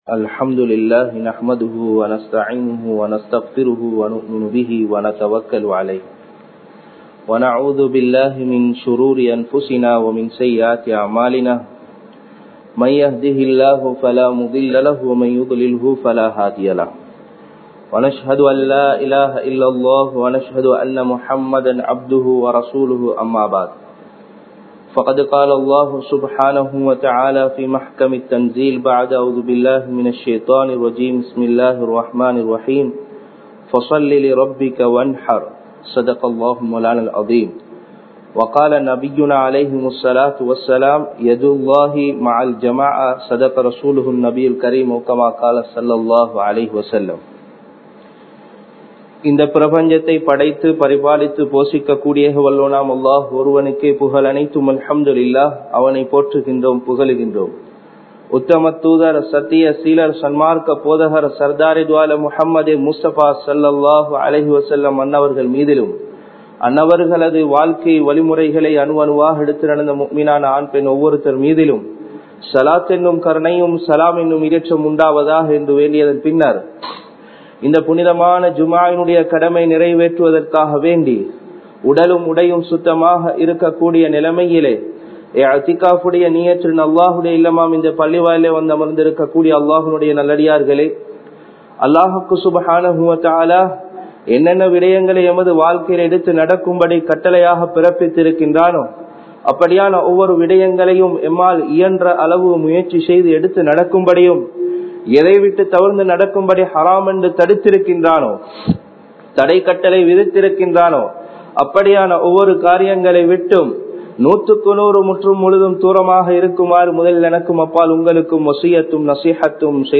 உழ்ஹிய்யாவுடைய அமல் (The Act of Ulhiyya) | Audio Bayans | All Ceylon Muslim Youth Community | Addalaichenai
Sabeelur Rashad Jumua Masjidh